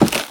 wood_i1.wav